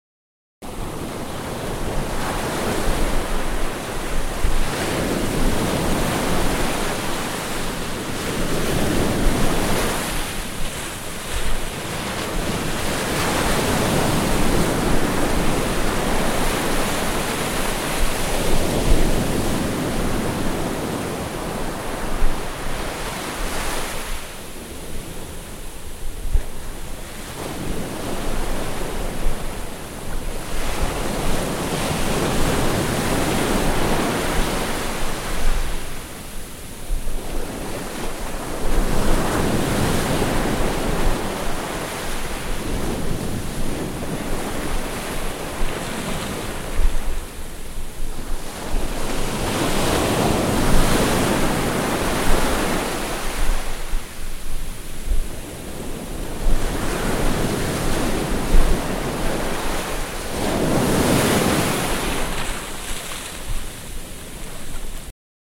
На этой странице собраны натуральные звуки пляжа: шум волн, легкий бриз, детский смех на песке и другие уютные аудиофрагменты.
Шум волн на ветреном морском пляже